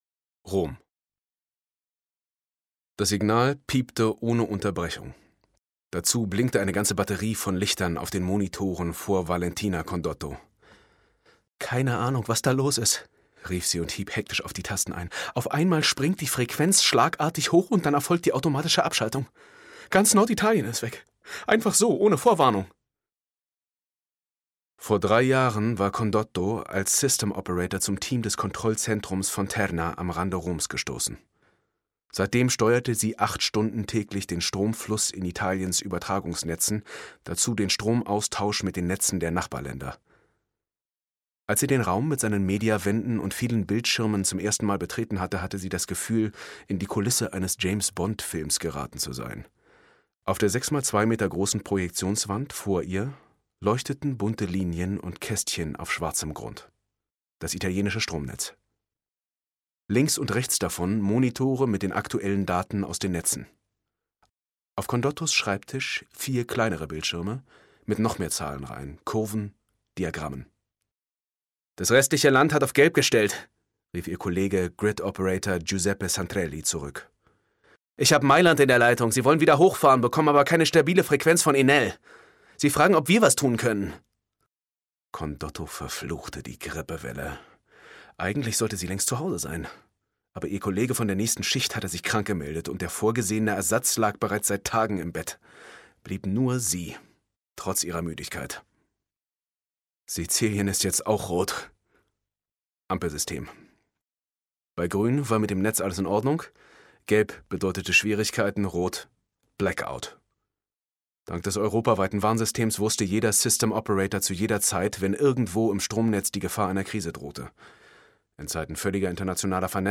BLACKOUT (DE) audiokniha
Ukázka z knihy
• InterpretSteffen Groth